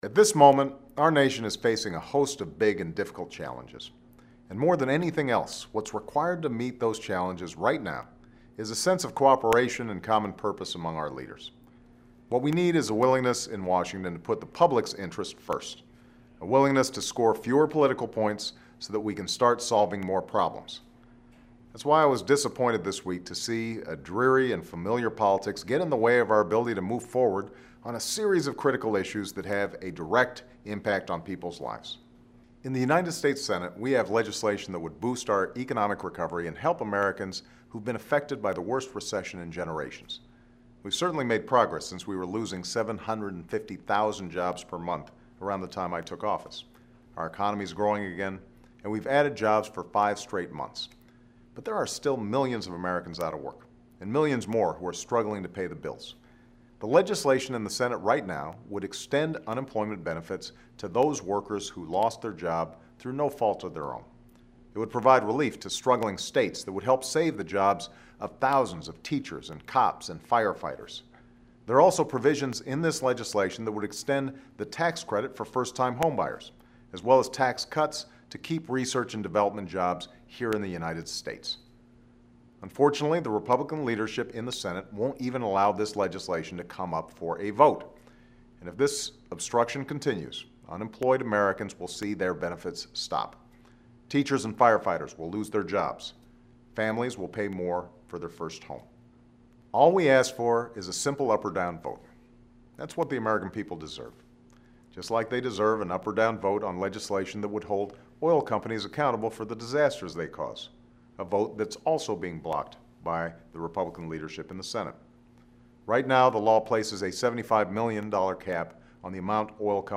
Prepared Remarks of President
Weekly Address